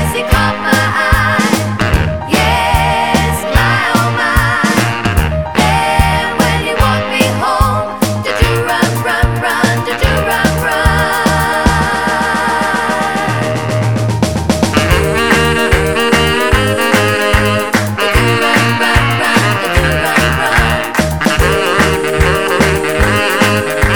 no Backing Vocals Soul / Motown 2:34 Buy £1.50